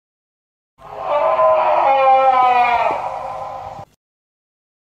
Scream Of The Undead Téléchargement d'Effet Sonore
Scream Of The Undead Bouton sonore